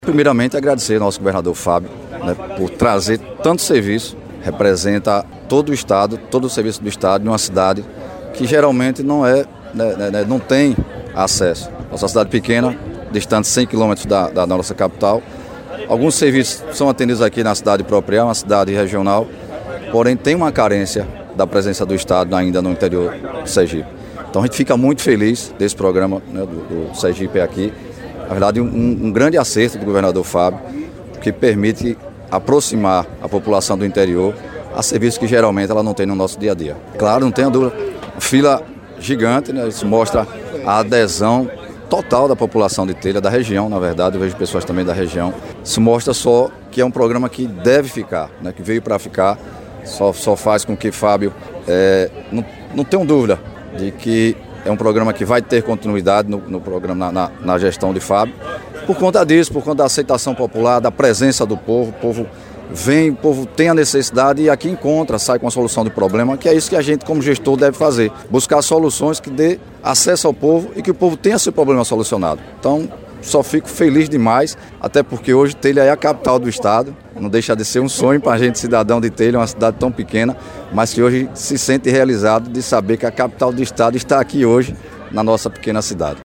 FÁBIO MITIDIERI, GOVERNADOR DE SERGIPE
FLAVIO DIAS, PREFEITO DE TELHA
LUIZ ROBERTO, SECRETÁRIO DE ESTADO DO DESENVOLVIMENTO URBANO E INFRAESTRUTURA